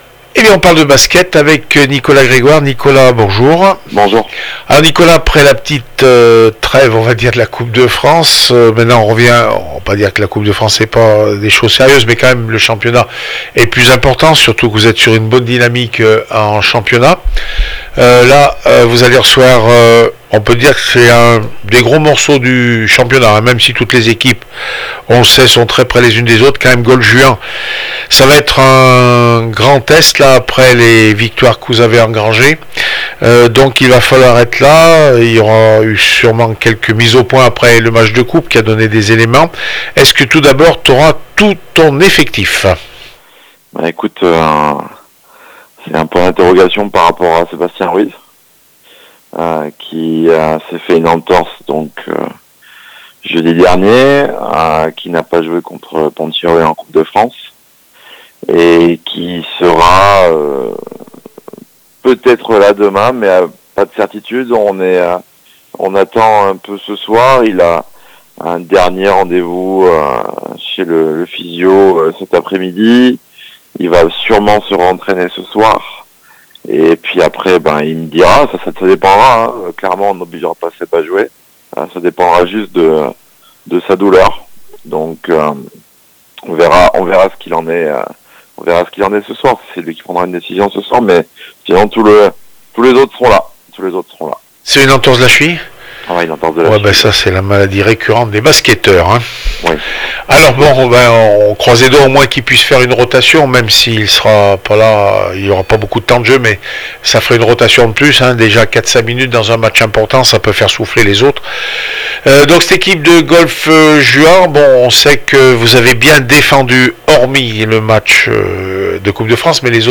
27 novembre 2015   1 - Sport, 1 - Vos interviews, 2 - Infos en Bref   No comments